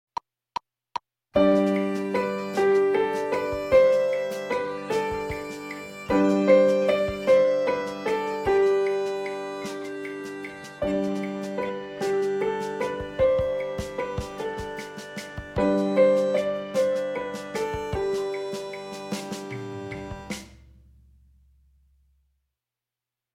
합주